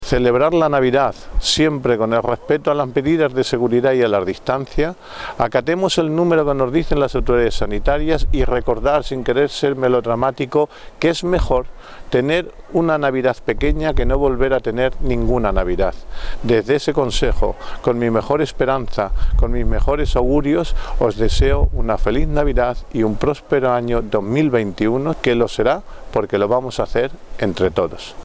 El alcalde de Valdepeñas, Jesús Martín, hace en su tradicional mensaje de Navidad un llamamiento a la ciudadanía para que se cumplan todas las medidas de seguridad en estas fiestas.